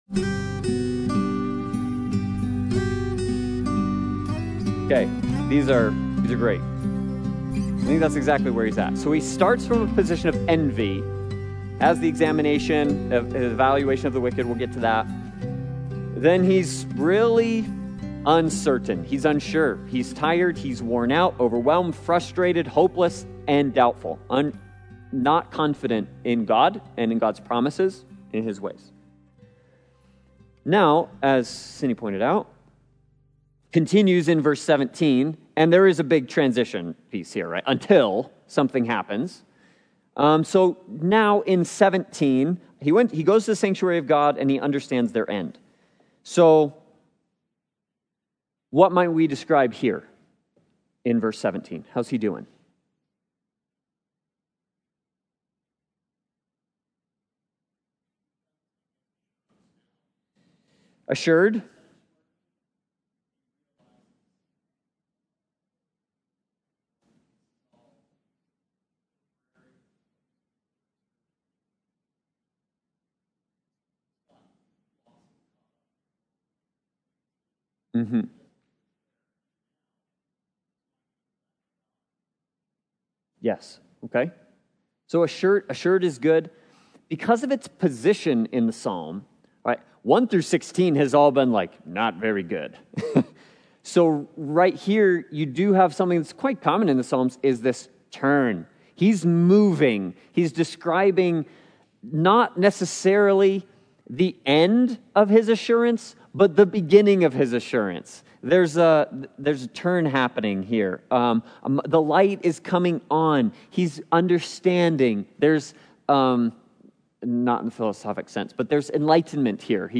Sunday Bible Study « A Wise Community